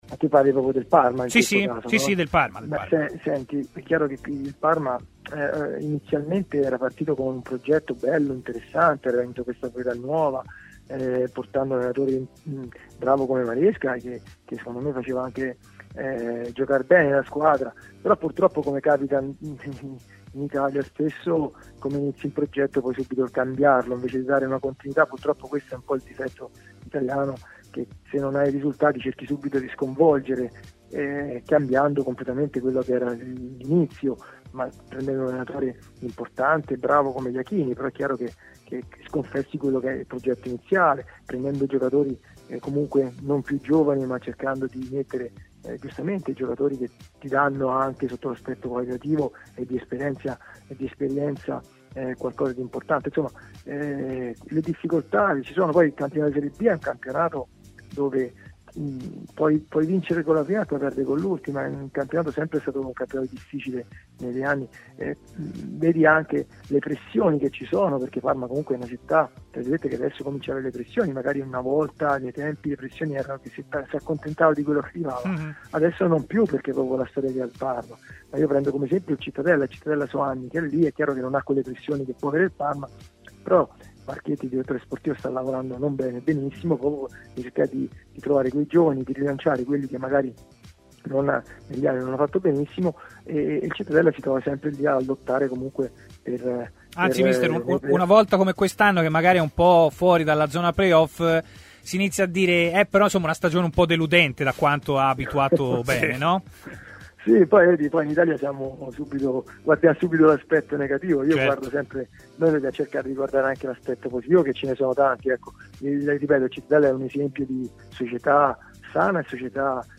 Intervenuto sulle frequenze di TMW Radio, l'ex difensore gialloblù Luigi Apolloni ha così commentato l'arrivo sulla panchina ducale di Fabio Pecchia: "Il Parma ha fatto una scelta mirata prendendo un allenatore che ha vinto il campionato in questa stagione.